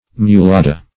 Search Result for " mulada" : The Collaborative International Dictionary of English v.0.48: Mulada \Mu*la"da\, n. A moor.